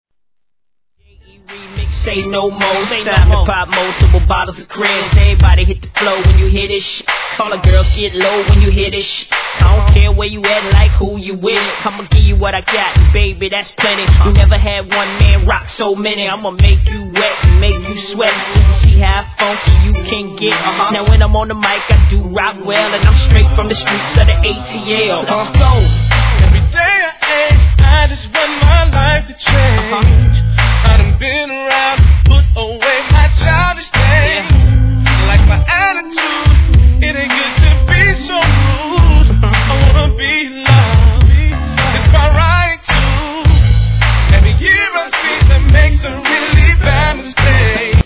1. HIP HOP/R&B
音質もバッチリです♪